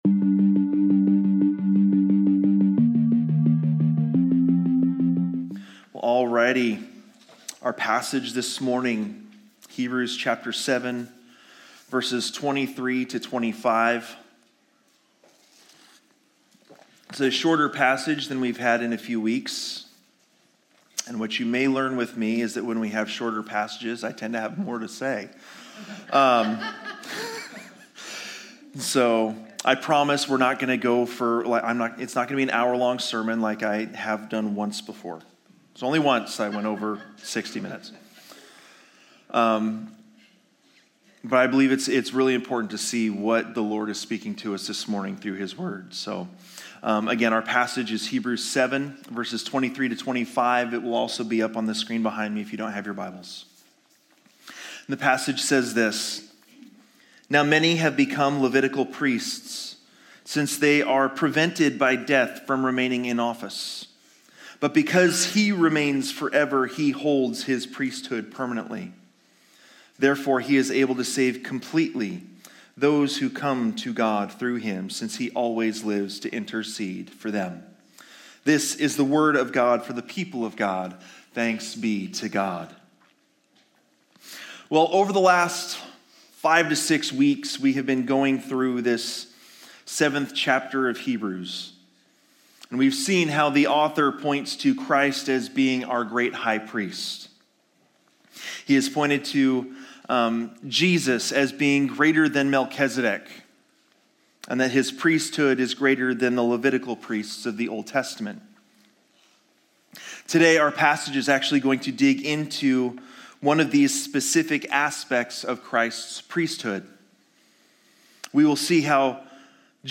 Sermons | Living Word Community Church